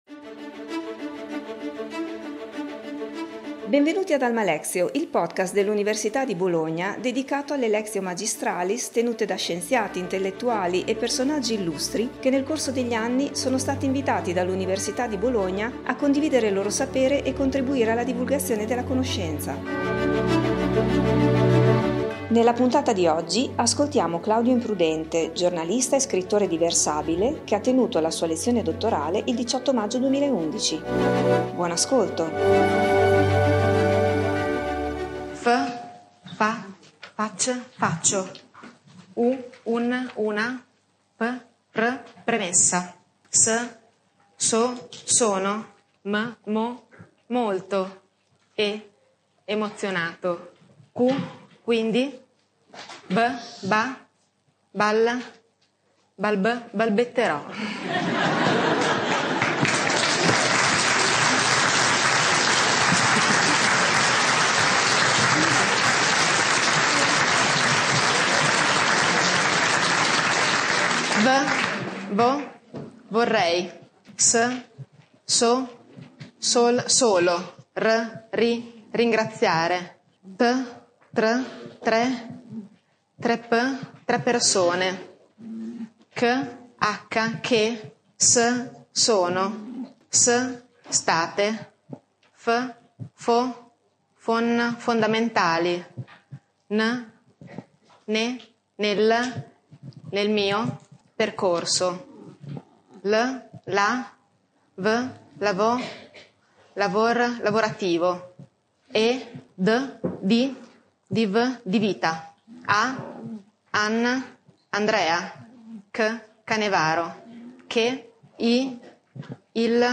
ha tenuto la sua Lectio Magistralis il 18 maggio 2011 durante il conferimento della laurea ad honorem in Formazione e cooperazione dell’Università di Bologna.